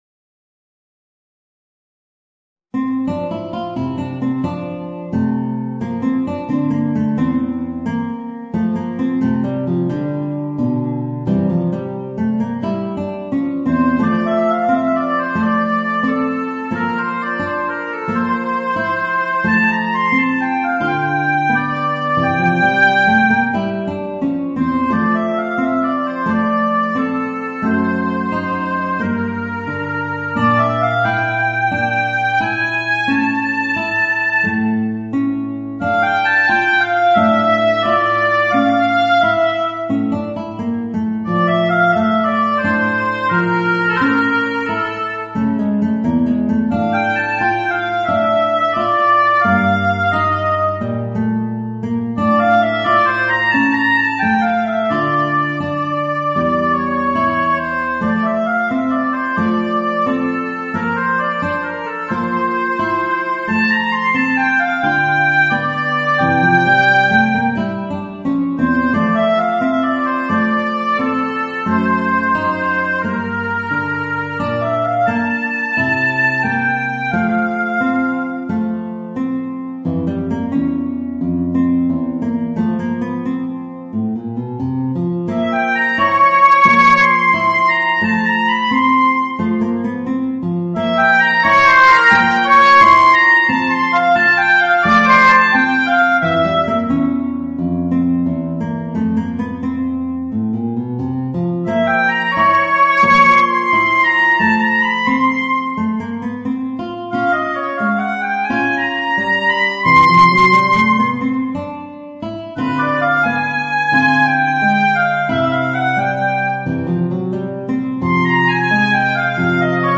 Voicing: Oboe and Guitar